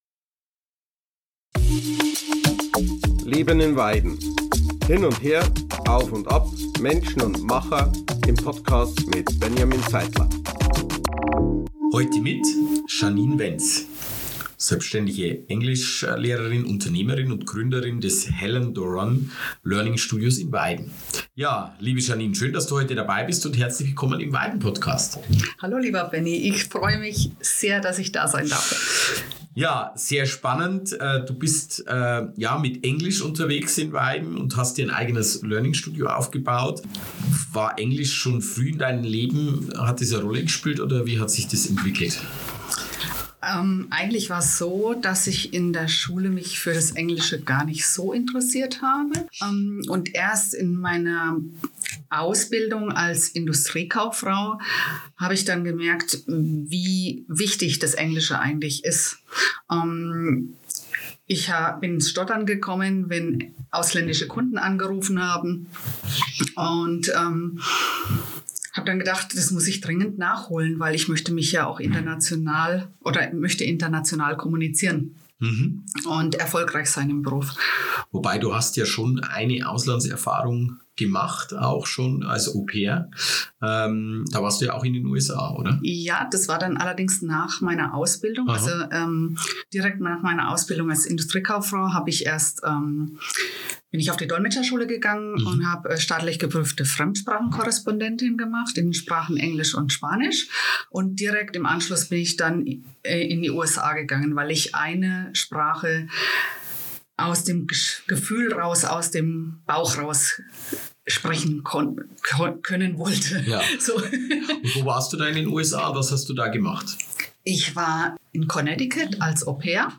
Ein offenes und inspirierendes Gespräch über Sprache, Unternehmertum, Familie und die Kraft, Dinge wirklich richtig zu machen.